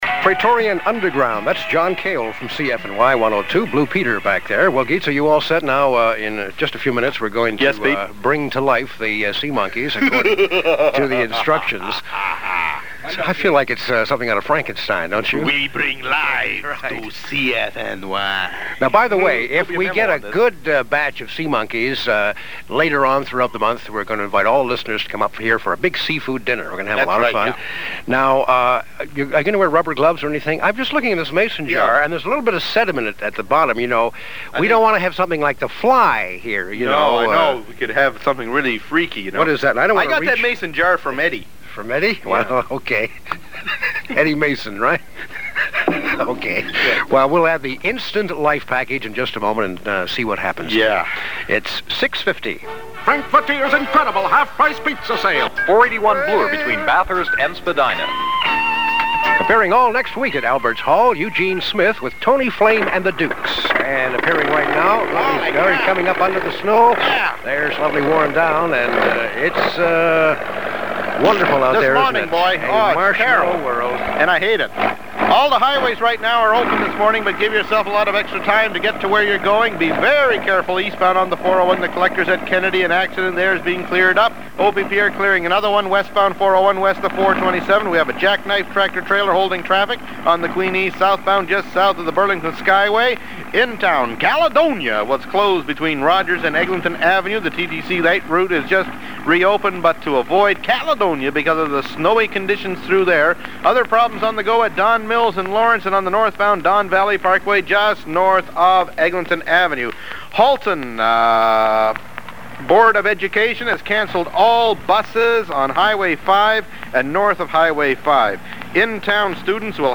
AIRCHECK OF THE WEEK Edition #1386 Week of February 22
Station: CFNY Toronto